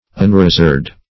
\Un*ra"zored\